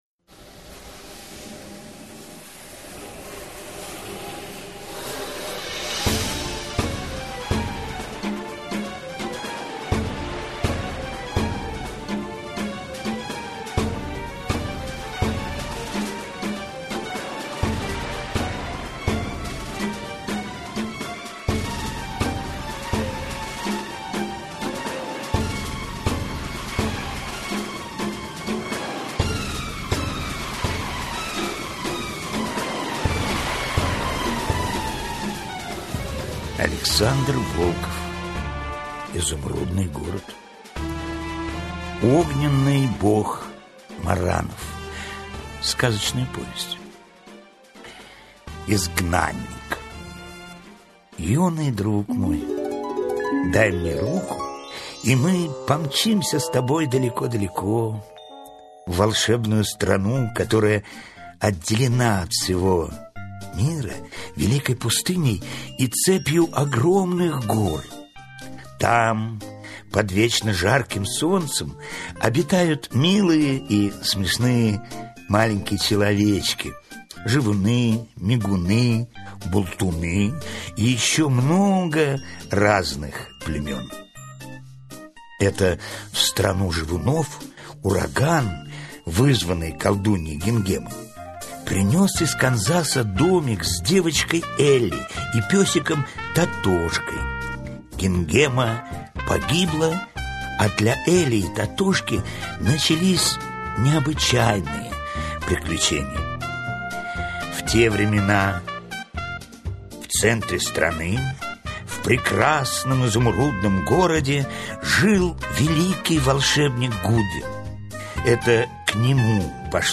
Аудиокнига Огненный бог Марранов | Библиотека аудиокниг